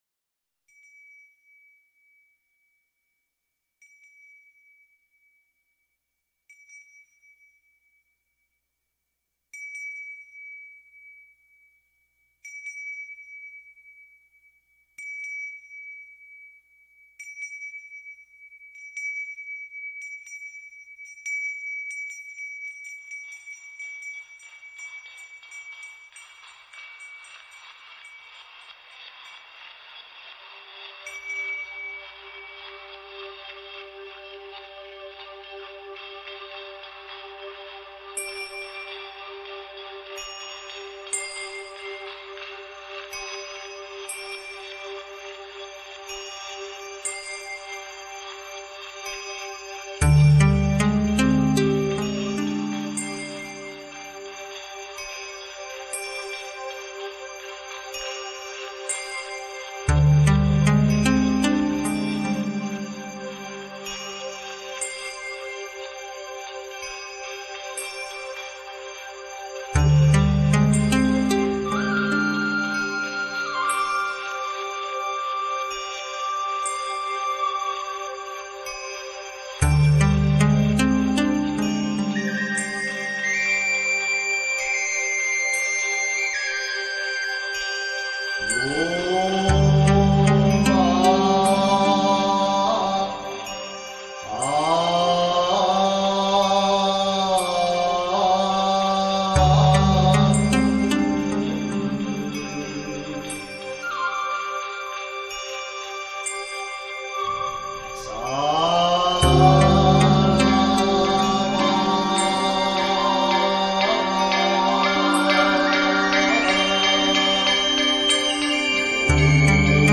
而且兩張專輯選曲風格各異，一張是舒緩平和的古韻，一張是暢快迷幻的時尚感，正扣CD面上分別所書的“平”、“月”二字。